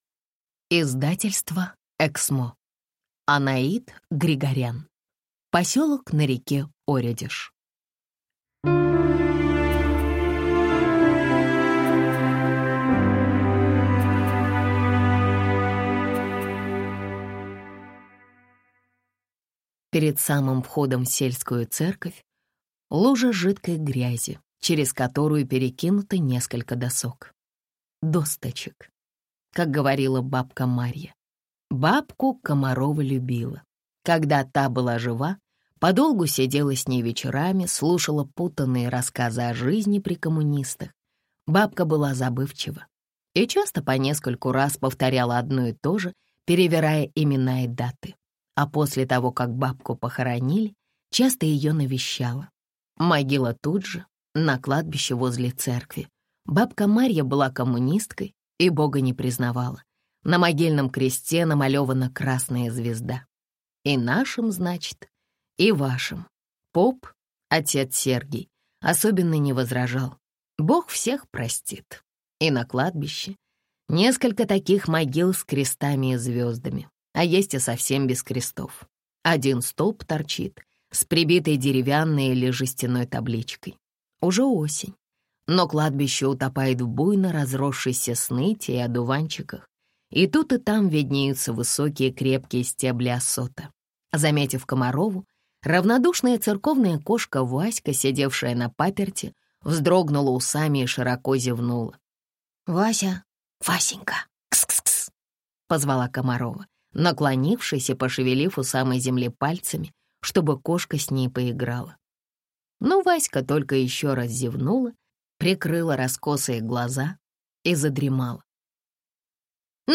Аудиокнига Поселок на реке Оредеж | Библиотека аудиокниг